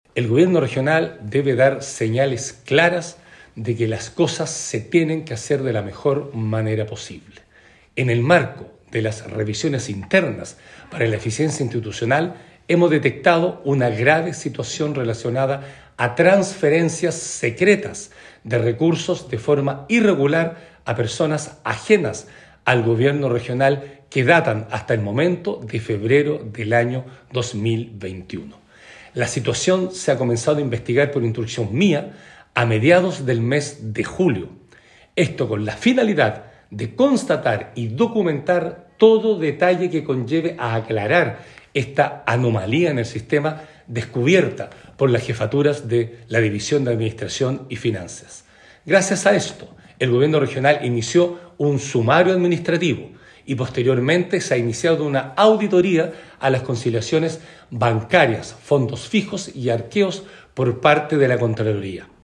Así lo informó el Gobernador Regional Patricio Vallespin, quien enfatizó que esta es una situación grave que se daba a personas ajenas al Gore Los Lagos, ya que se estaría constatando un sistema de desviación de fondos públicos por lo que se ha ordenado una auditoría externa.
16-agosto-23-patricio-vallespin-auditoria.mp3